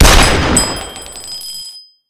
shell.ogg